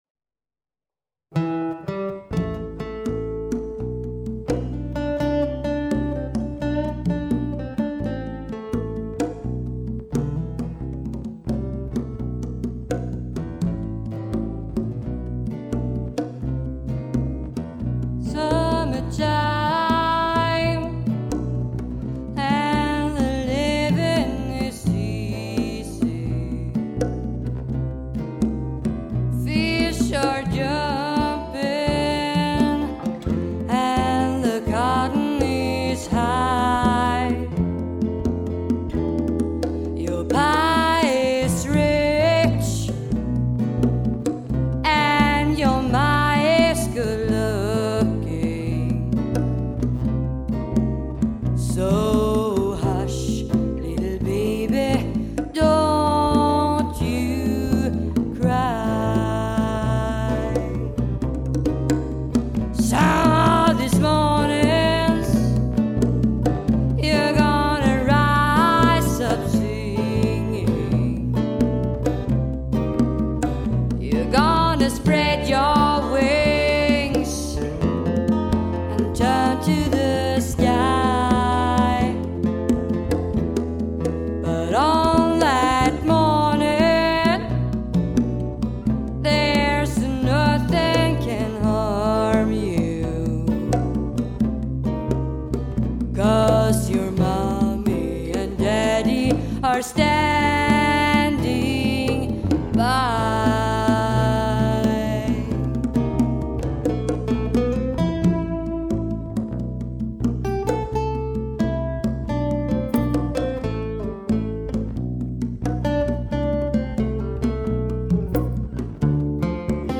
sång